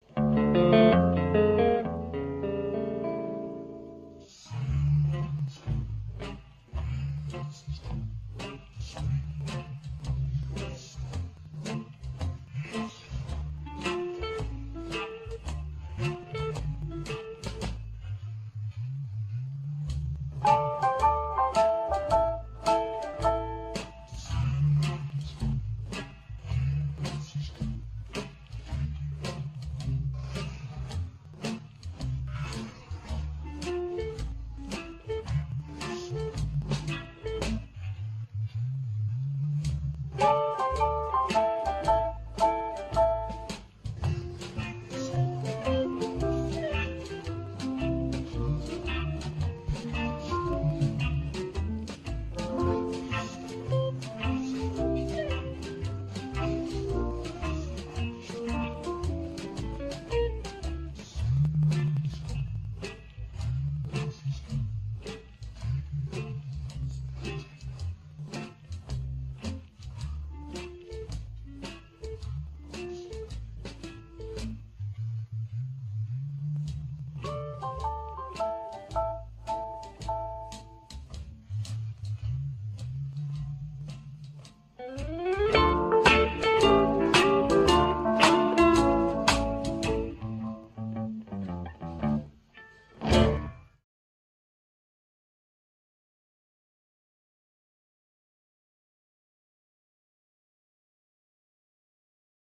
(CANDYLAND)-vocal